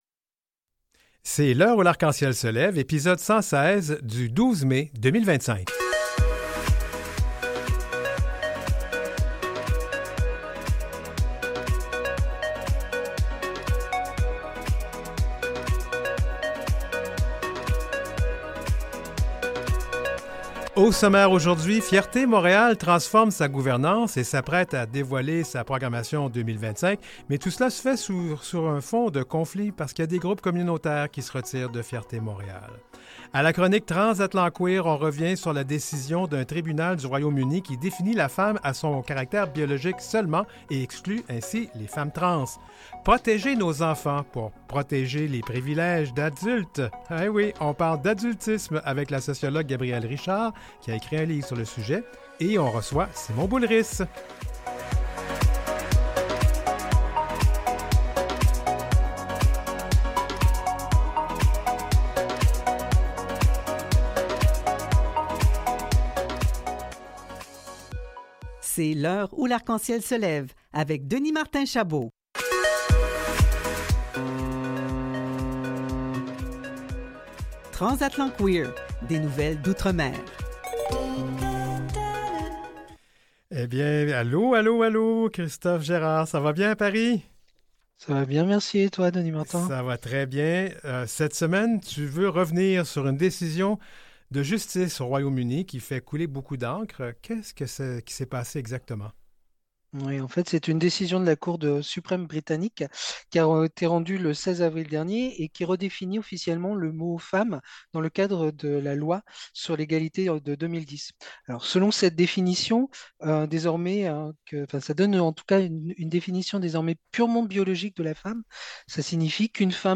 Un espace hebdomadaire inclusif, des échanges ouverts, des invité.e.s au coeur de l’actualité et des débats, une heure dédiée à l’actualité et aux enjeux touchant les personnes des communautés de la diversité des orientations sexuelles et des affirmations de genre. L’heure où l’arc-en-ciel se lève, la première émission radiophonique de langue française pancanadienne dédiée à la diversité 2ELGBTQIA +.